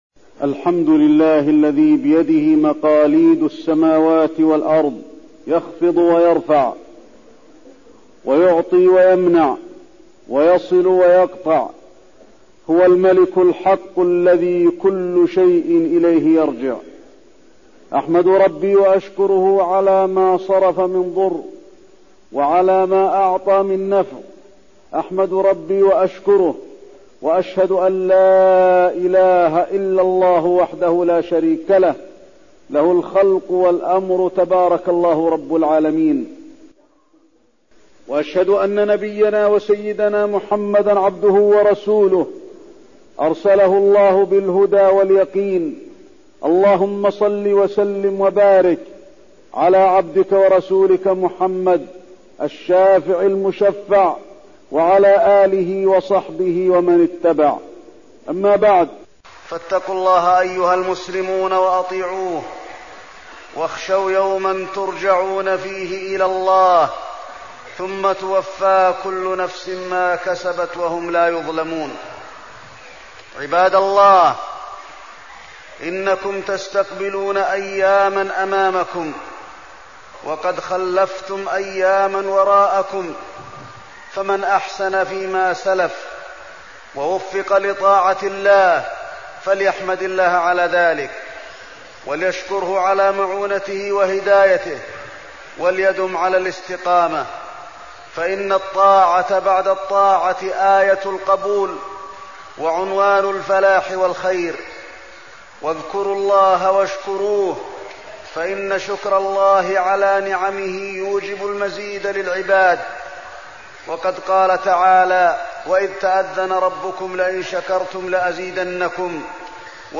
تاريخ النشر ٢ شوال ١٤١٥ هـ المكان: المسجد النبوي الشيخ: فضيلة الشيخ د. علي بن عبدالرحمن الحذيفي فضيلة الشيخ د. علي بن عبدالرحمن الحذيفي طاعة الله وشكره